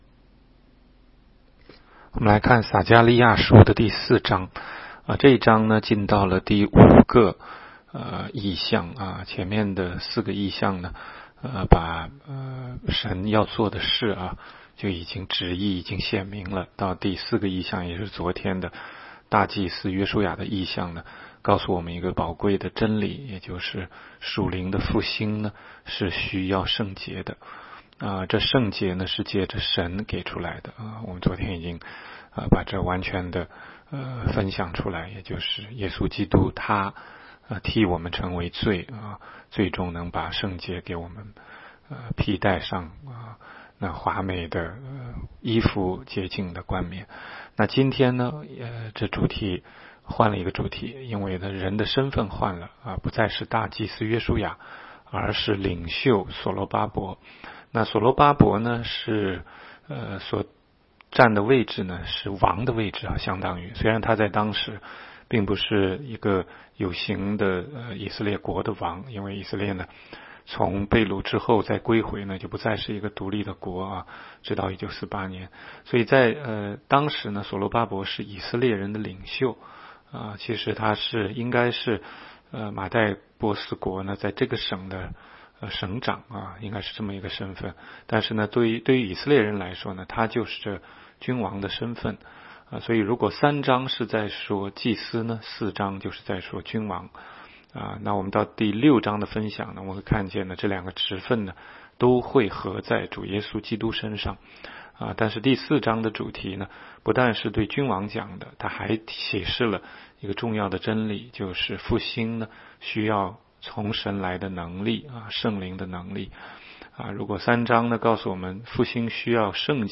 16街讲道录音 - 每日读经 -《撒迦利亚书》4章